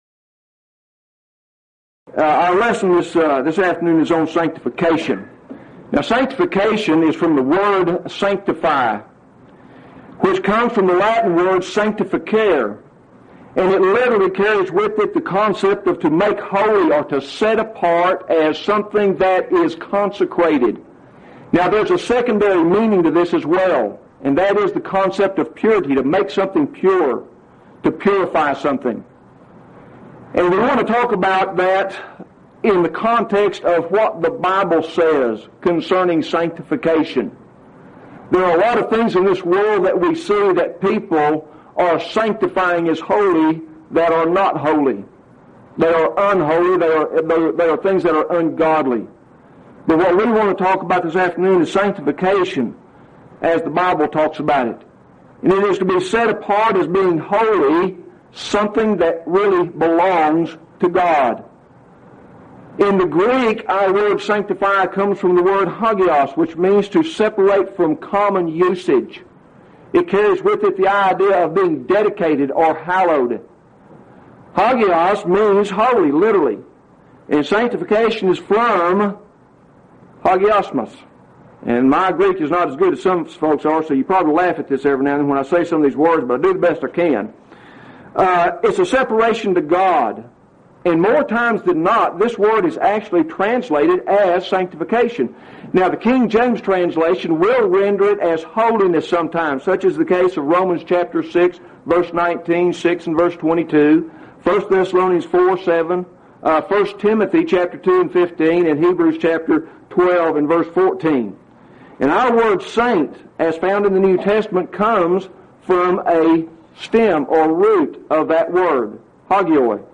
Event: 2nd Annual Lubbock Lectures